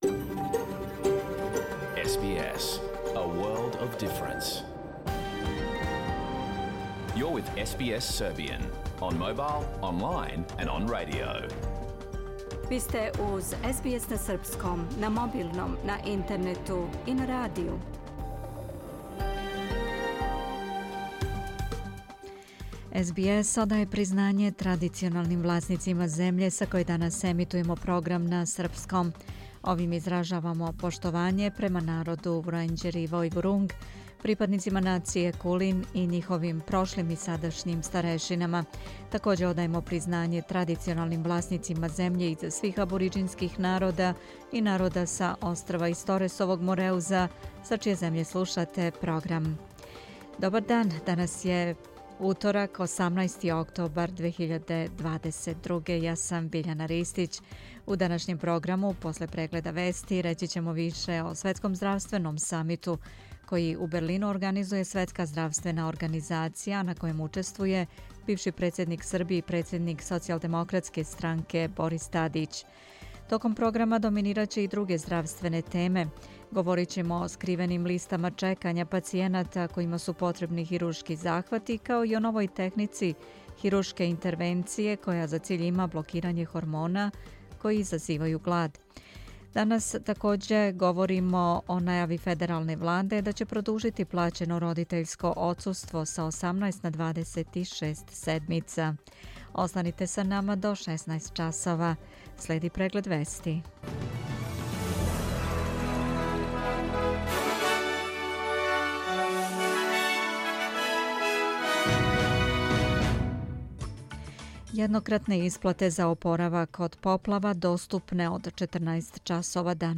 Програм емитован уживо 18. октобра 2022. годинe